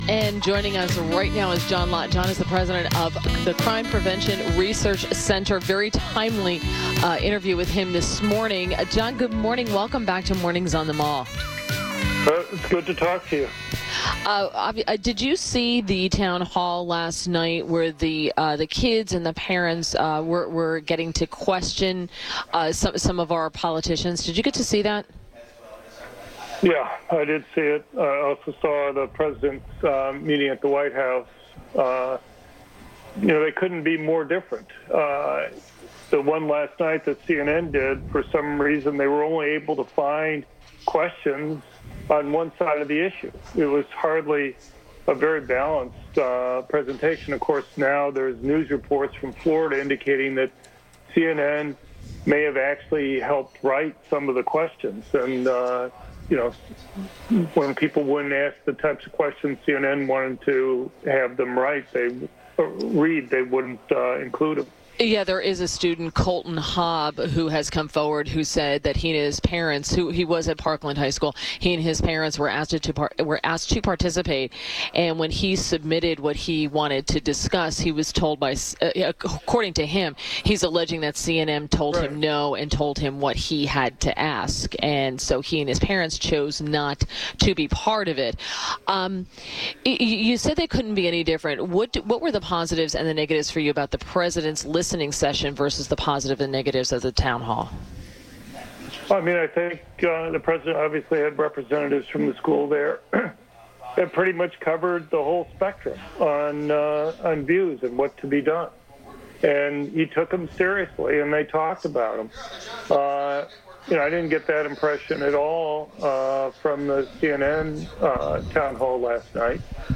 WMAL INTERVIEW - CPAC JOHN LOTT - 02.22.18